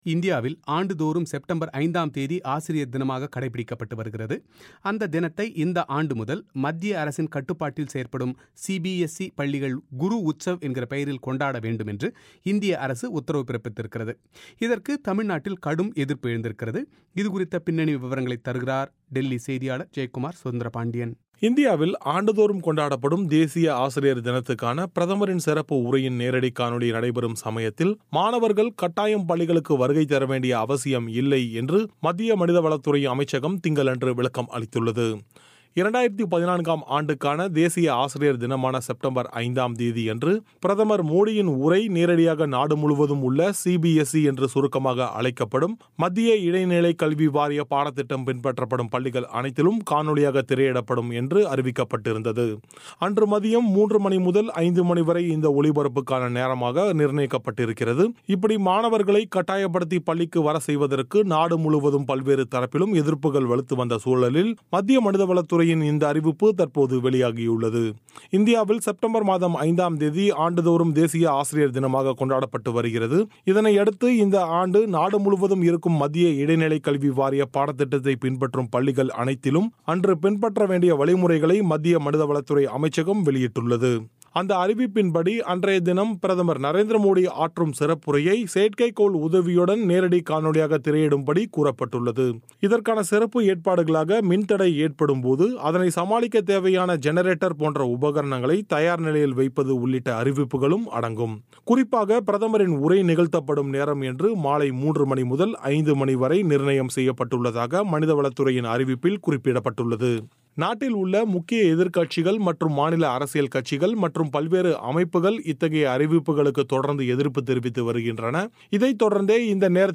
இது தொடர்பாக அவர் பிபிசி தமிழோசைக்கு அளித்த பிரத்யேக செவ்வியை நேயர்கள் இங்கே விரிவாக கேட்கலாம்.